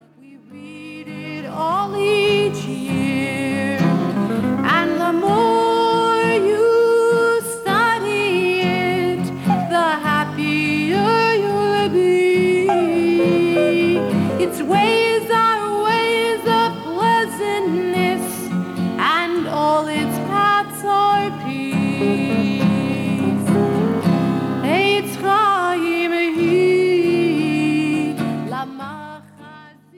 A Jewish sing-a-long for families!